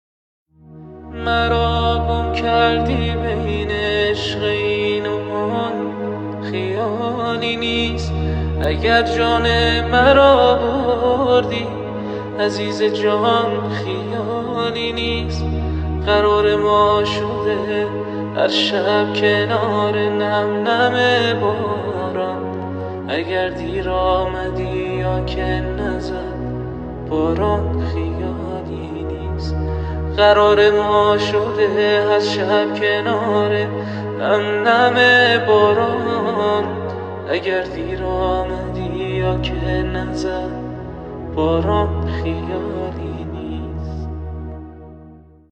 دانلود آهنگ غمگین جدید